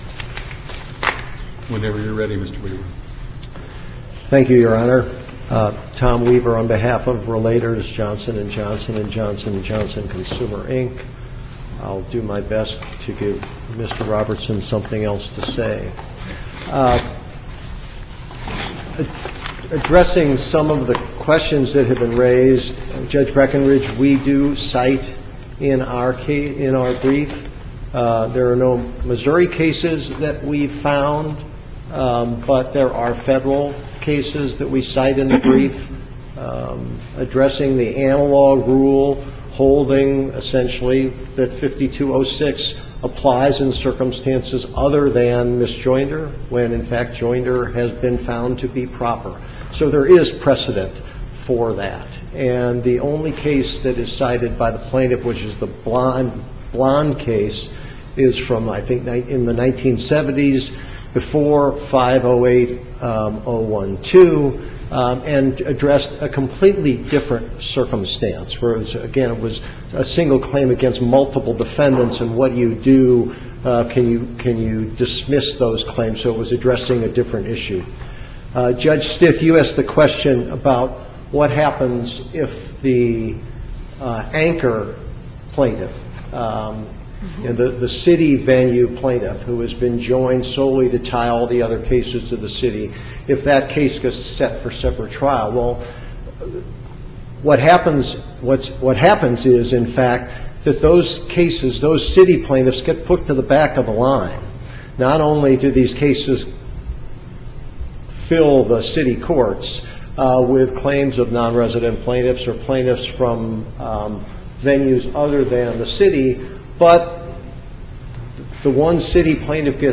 MP3 audio file of arguments in SC96718